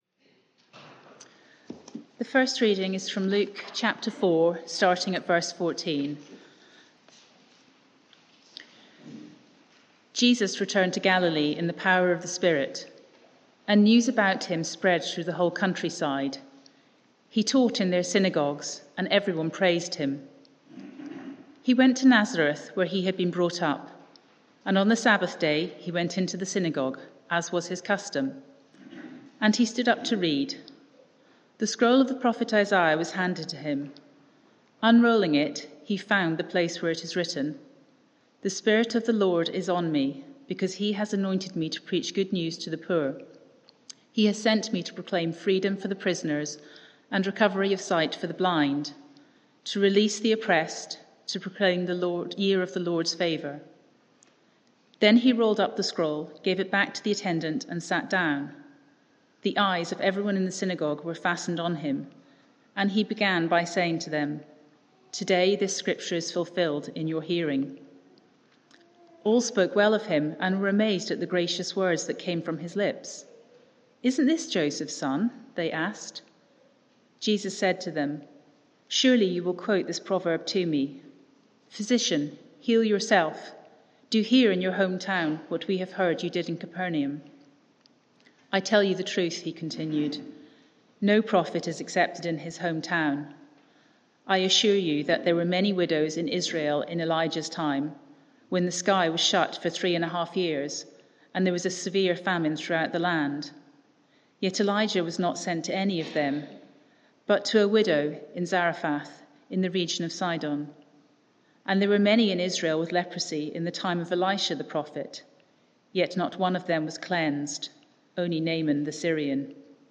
Media for 9:15am Service on Sun 10th Apr 2022
Passage: Isaiah 61:1-9 Series: The Servant King Theme: The year of the Lord's favour Sermon (audio)